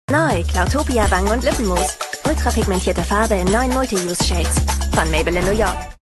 plakativ, sehr variabel
Jung (18-30)
Commercial (Werbung)